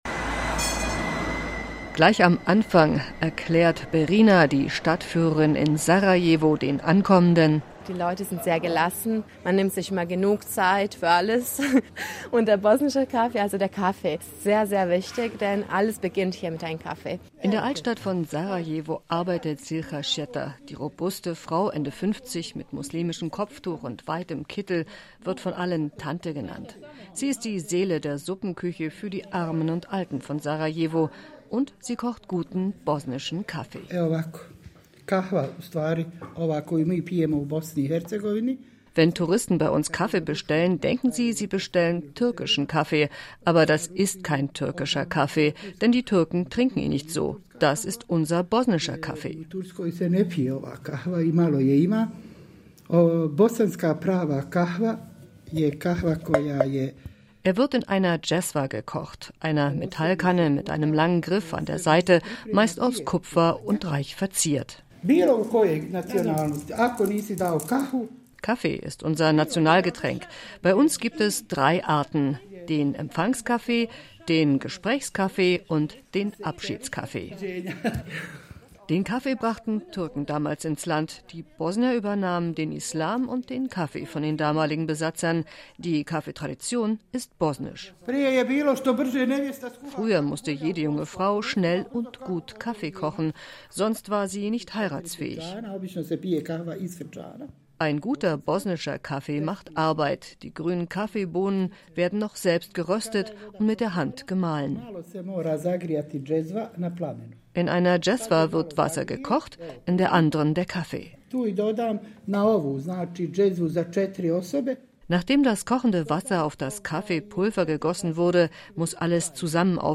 Wie man bosnischen Kaffee kocht. Eine Reportage